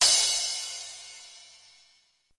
电子碰撞（8）立体声
描述：数字撞镲与立体声效果
Tag: 碰撞 鼓数字